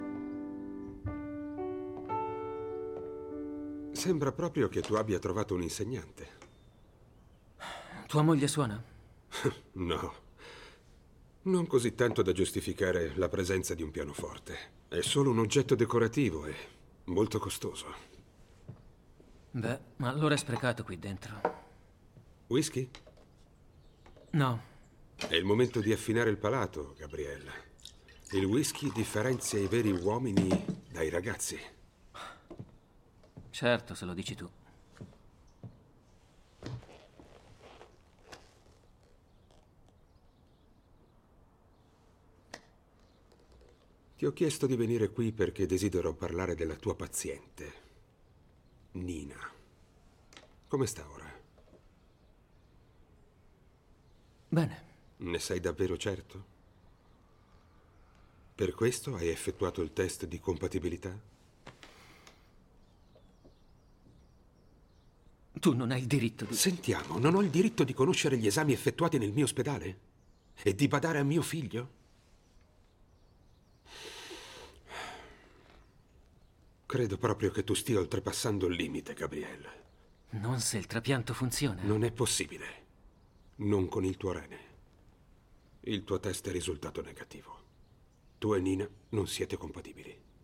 nel film "Oltre l'universo", in cui doppia João Miguel.